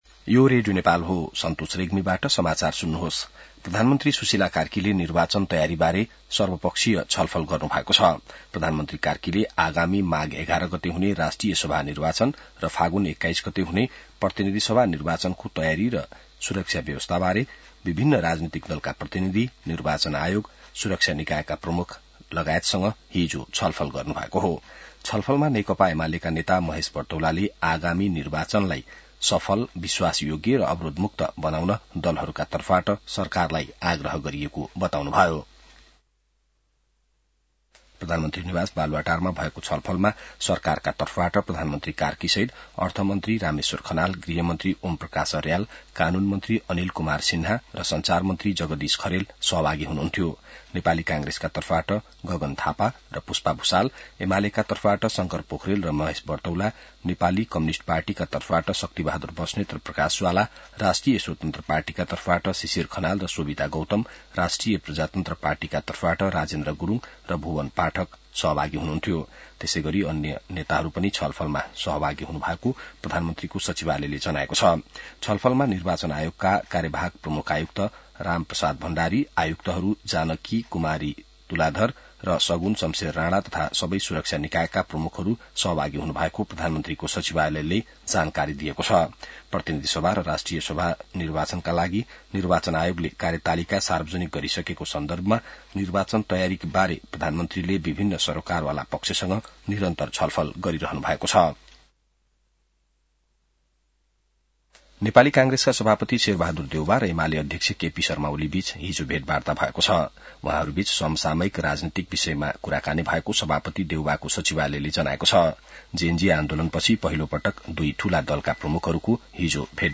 बिहान ६ बजेको नेपाली समाचार : २० मंसिर , २०८२